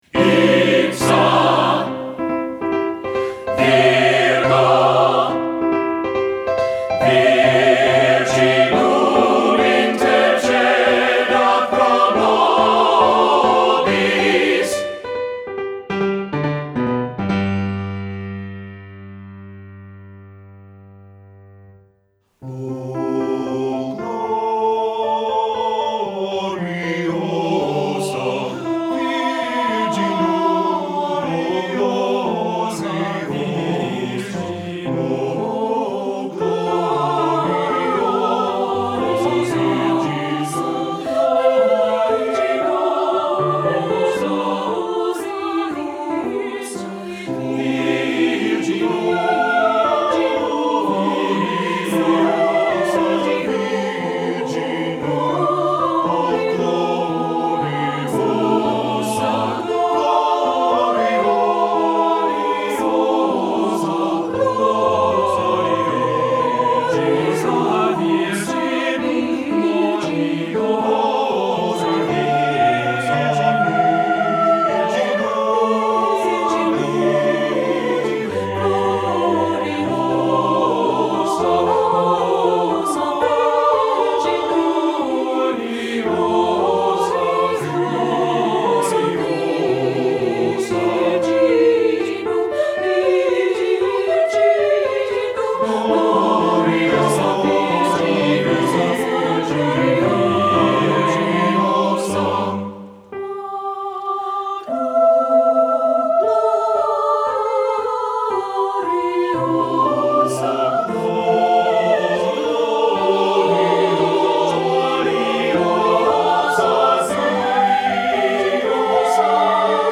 Soprano, Alto, Tenor, Bass, Piano, Oboe 1, Oboe 2,...
Studio Recording
Ensemble: Mixed Chorus
Accompanied: Accompanied Chorus